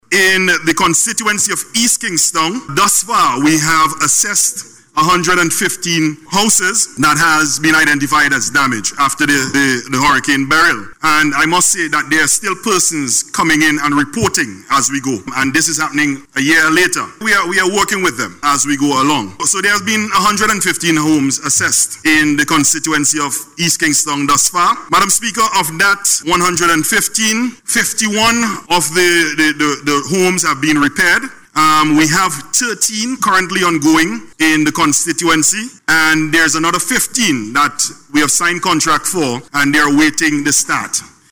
Speaking in Parliament, Minister of Housing Dr. Orando Brewster said that of the 115 houses assessed, 51 have been repaired.